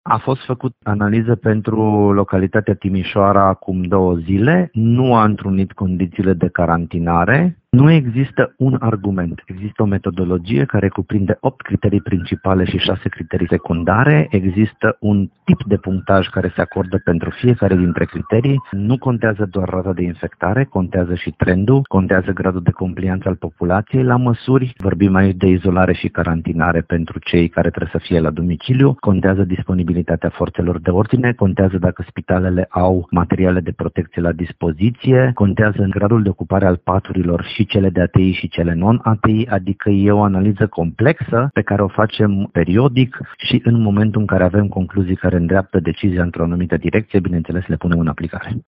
Municipiul Timișoara nu va intra, cel puțin, pentru moment, în carantină. Cea mai recentă analiză realizată de DSP a arătat că măsura nu se impune, deocamdată, a declarat pentru Radio Timișoara, șeful instituției, Flavius Cioca.